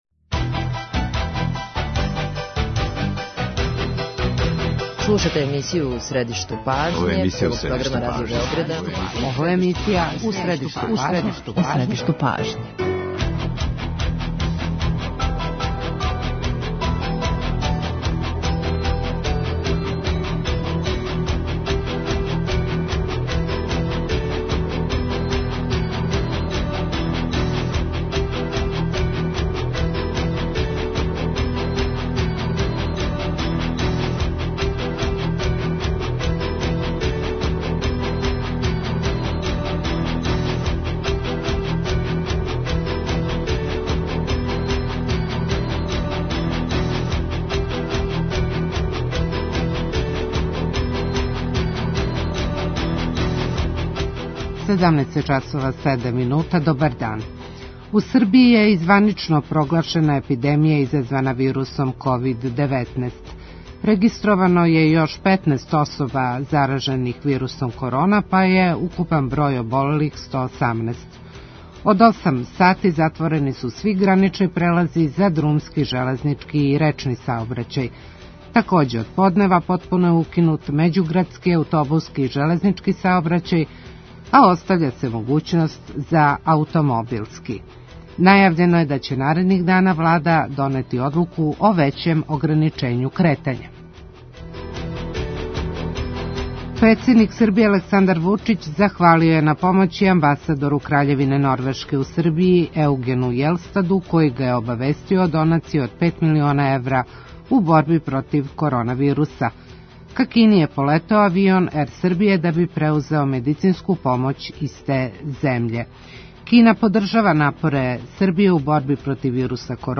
Саговорница емисије је Драгана Станић, вицегувернер Народне банке Србије.